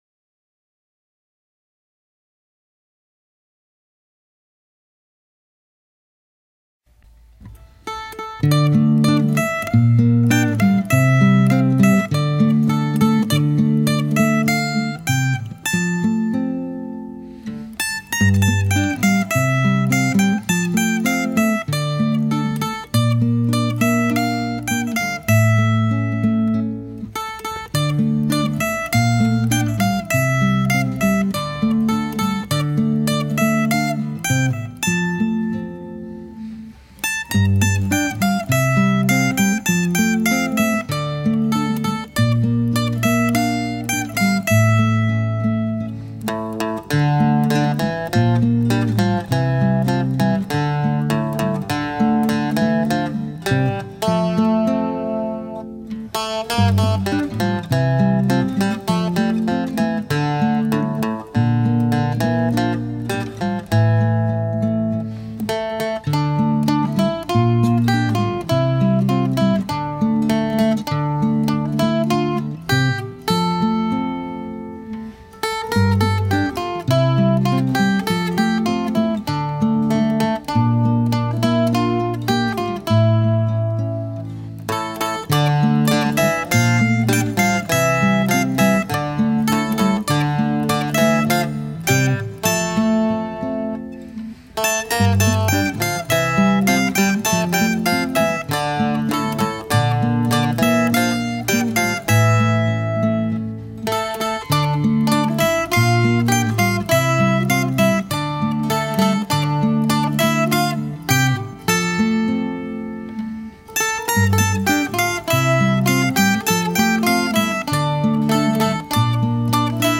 eendelige dansmuziek
Het betreft een muziekstuk dat zich telkens herhaalt in een andere muzikale variatie en interpretatie van de muzikant.
Het is een kringdans per koppel in vier verschillende bewegingen.